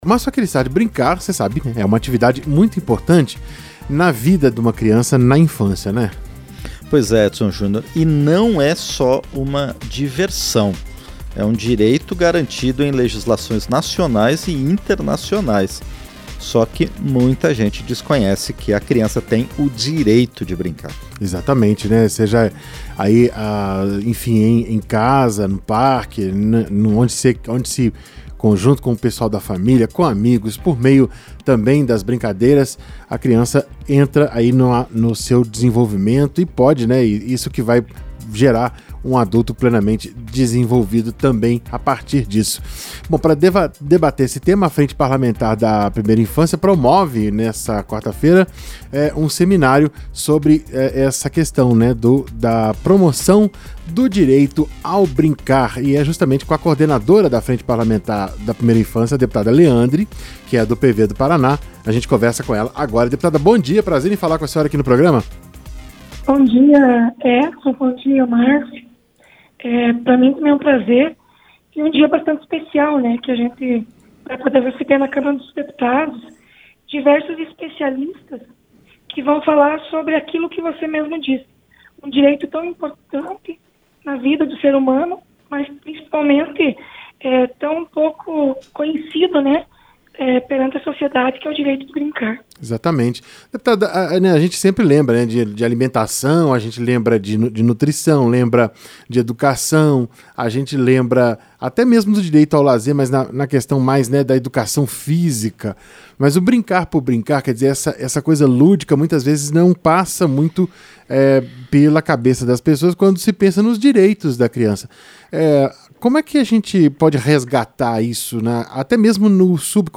Entrevista - Dep. Leandre (PV-PR)